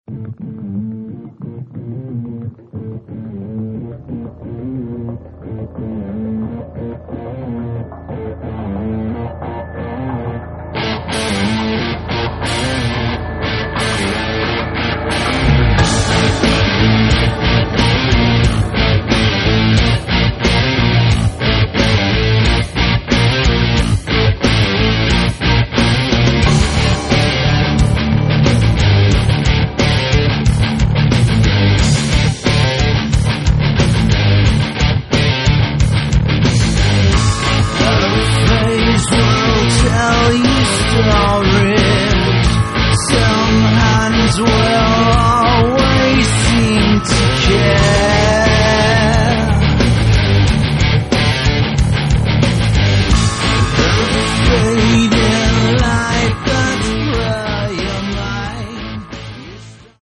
Рок
Более рок-н-ролльный дух, настроение шоу.